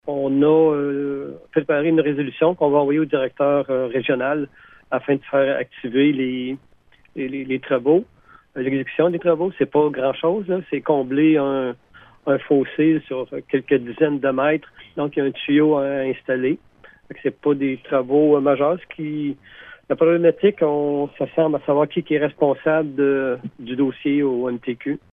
Bouchette attend que le ministère des Transports du Québec (MTQ) procède à des travaux visant à combler un fossé sur une dizaine de mètres avant de pouvoir aller de l’avant. Le maire, Gilles Bastien, donne plus de précision :